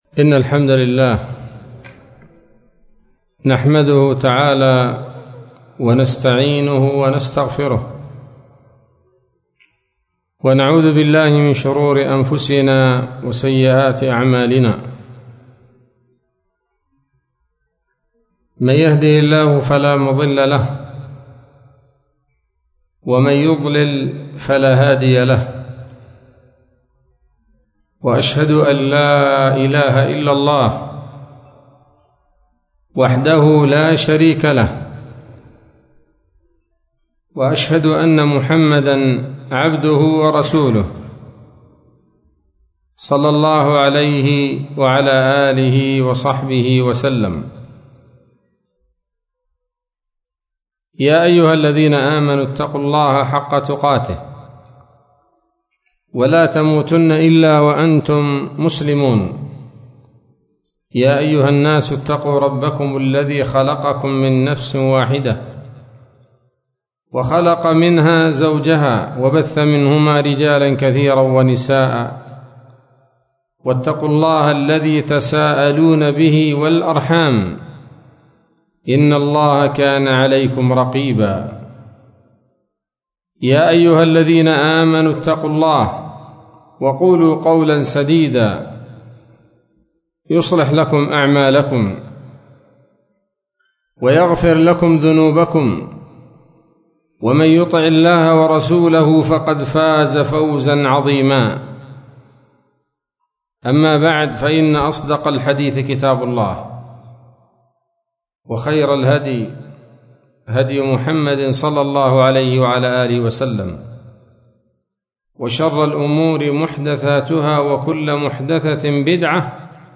محاضرة قيمة بعنوان: (( ‌في ازدياد العلم إرغام العدى )) ليلة الأربعاء 21 ذو الحجة 1443هـ، بدار الحديث السلفية - وادي حطيب - يافع